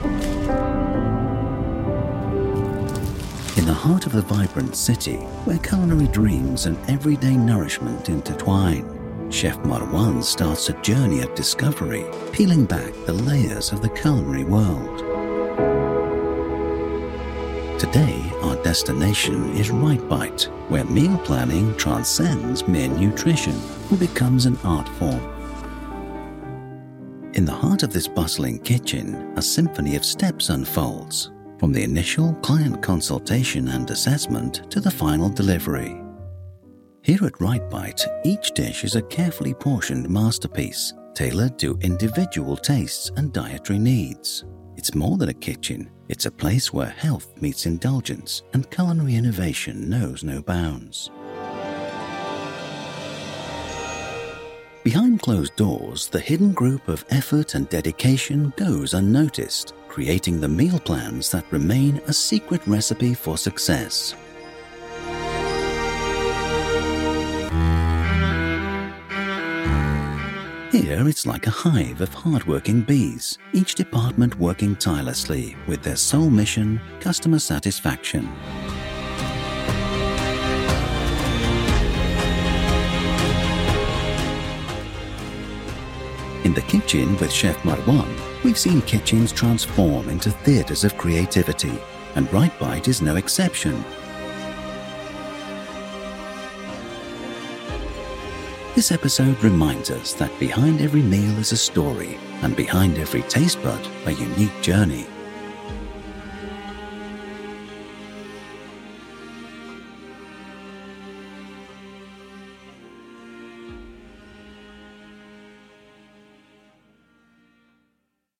Best TV Documentary VO – The Kitchen (Warner Bros Discovery)